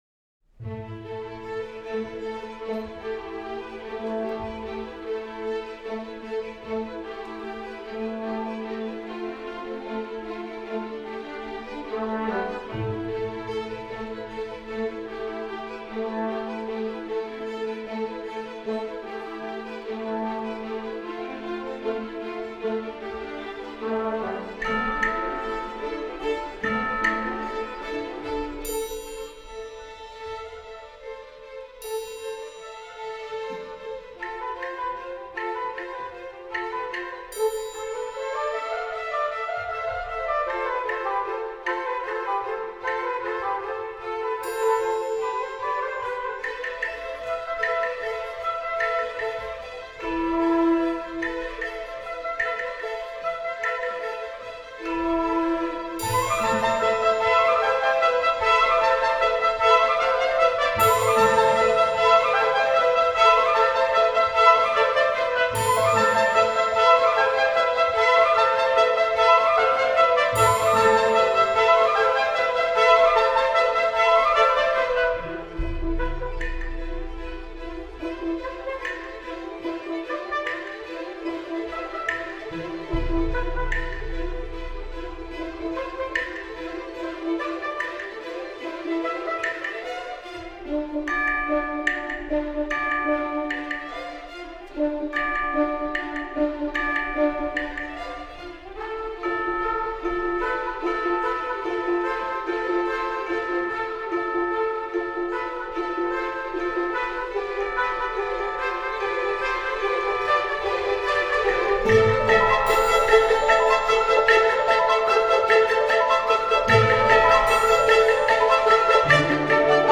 orchestrální verze z roku 2024
nahrávka z koncertu     partitura     notový materiál